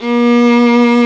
Index of /90_sSampleCDs/Roland L-CD702/VOL-1/STR_Viola Solo/STR_Vla2 % marc